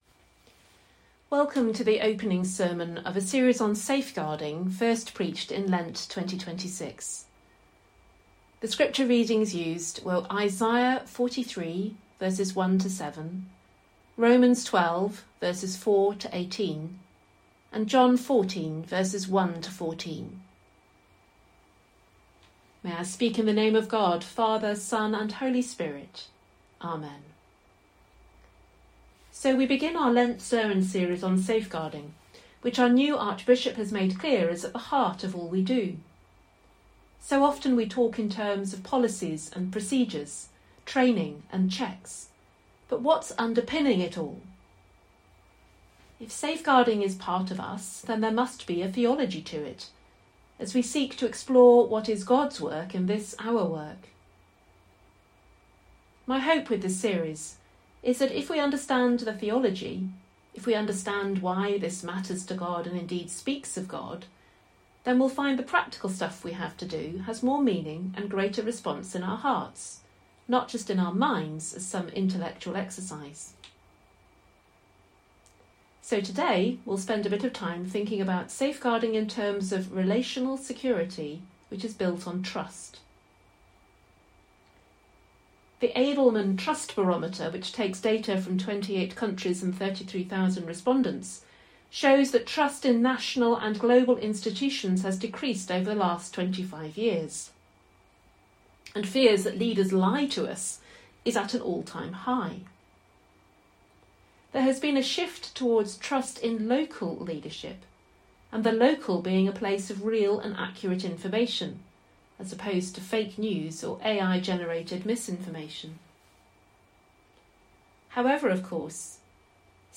A series of sermons originally preached in Lent 2026 looking at the theology and spirituality of safeguarding.